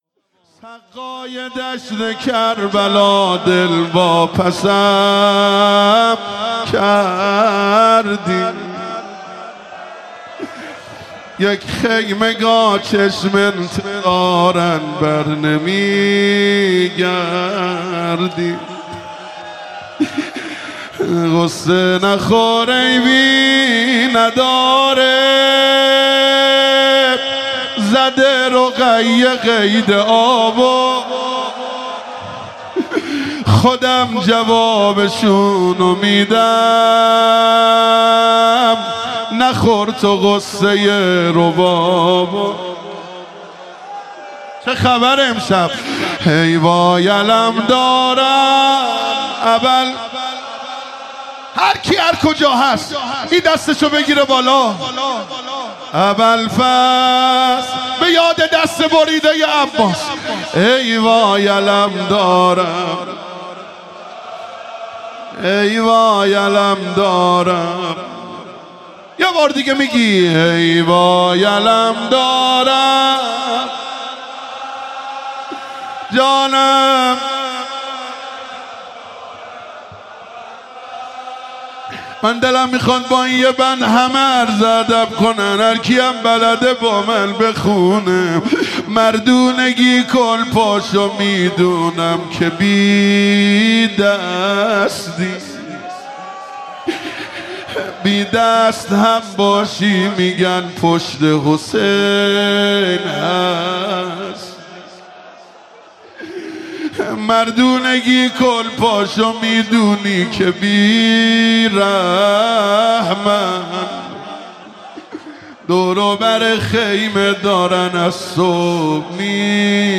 • سقا, روضه, شعر